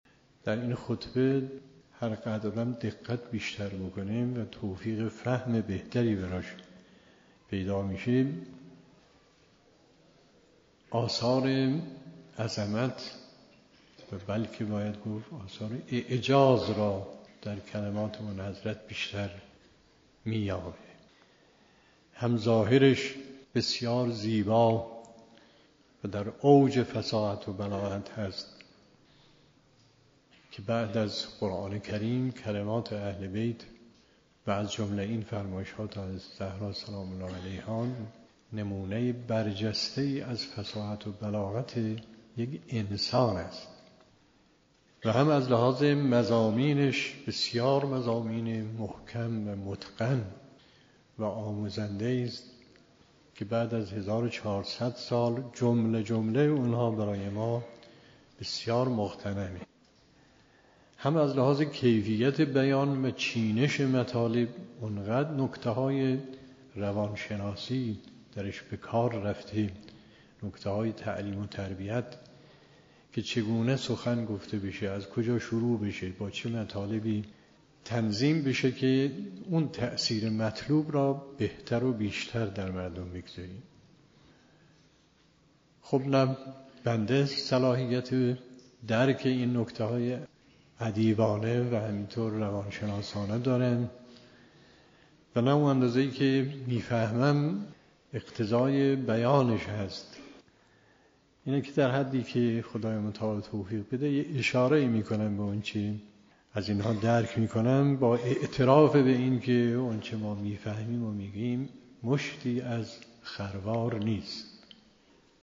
به گزارش خبرگزاری حوزه، به مناسبت ایام فاطمیه، گزیده‌ای از بیانات مرحوم آیت الله مصباح در رابطه با خطبه فدکیه حضرت زهرا سلام الله علیها تقدیم شما فرهیختگان می‌شود.